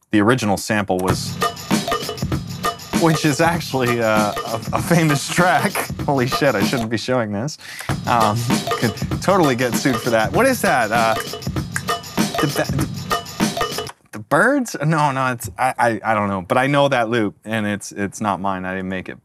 Перерыл кучу библ с олдскульными хип-хоп драм-лупами, есть что-то похожее (бочка, шейкер и каубэлл), но всё равно не то...
Snowcone loop.wav